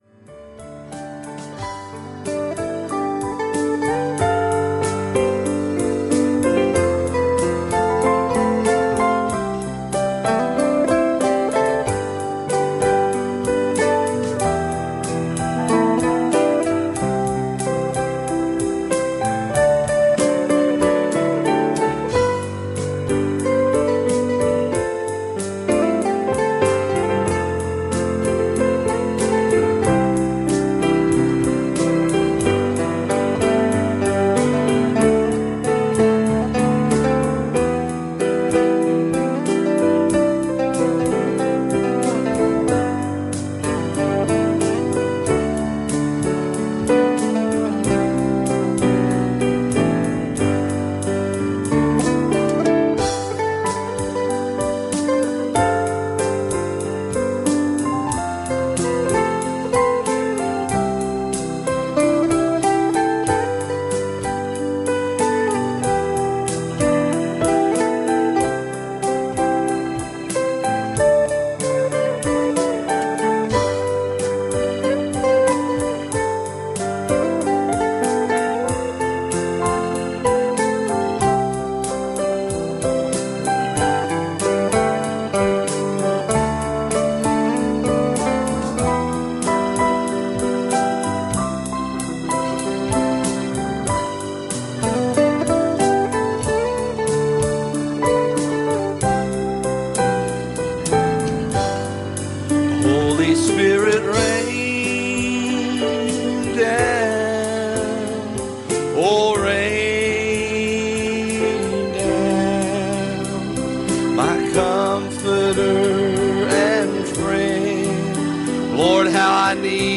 Passage: Matthew 23:25 Service Type: Sunday Morning